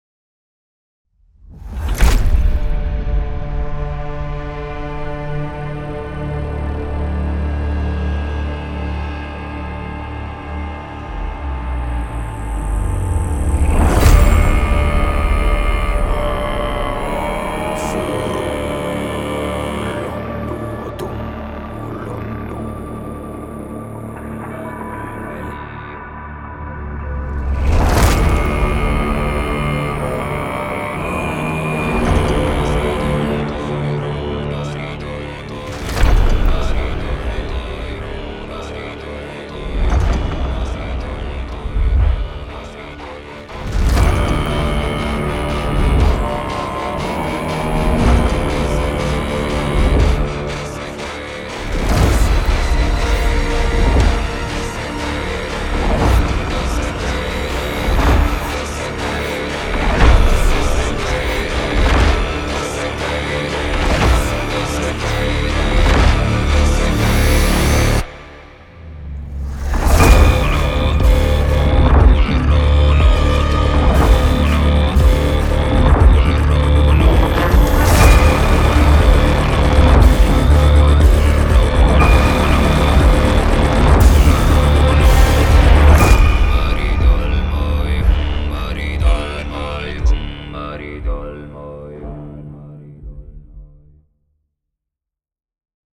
Throat singing library
Mystical cinematic sound